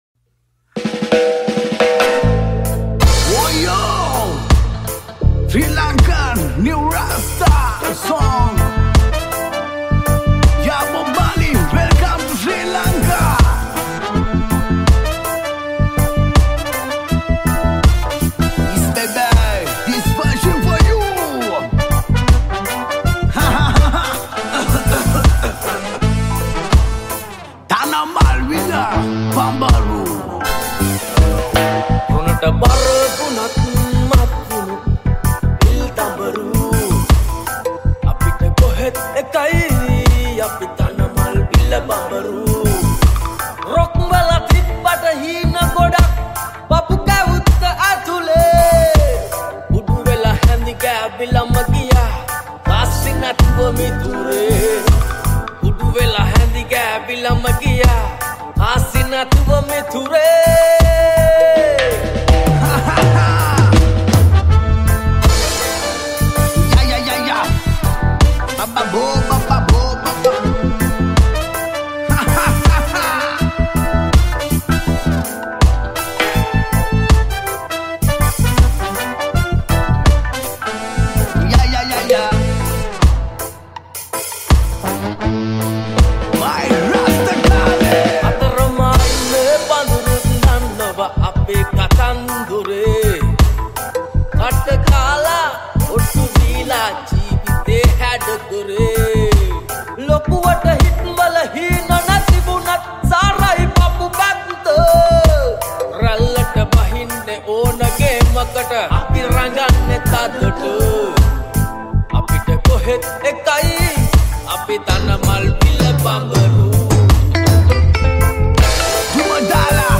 High quality Sri Lankan remix MP3 (3.6).
remix